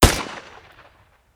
AR3_Shoot 01.wav